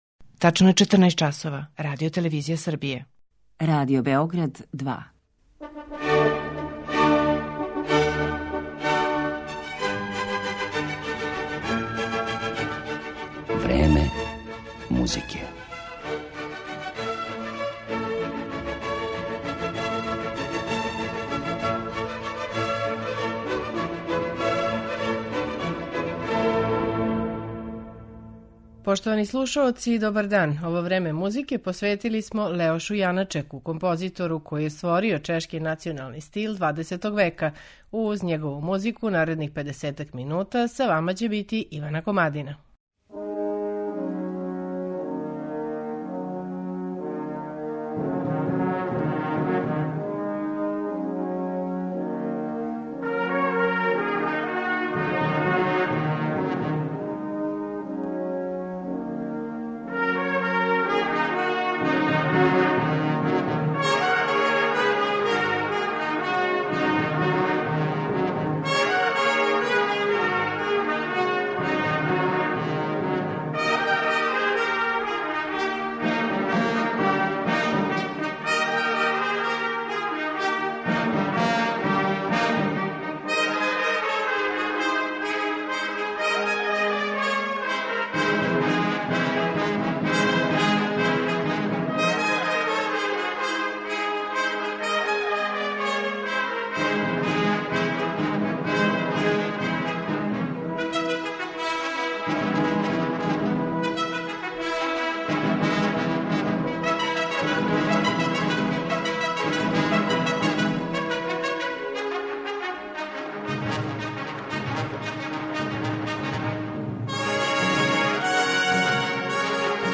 Управо о том знатно мање познатом аскпекту његове каријере, говорићемо у данашњем Времену музике. Слушаћете његове композиције: Симфонијета, Лашке игре, Моравске игре, клавирски циклус У измаглици.